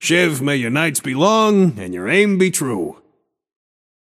Shopkeeper voice line - Shiv, may your nights be long, and your aim be true.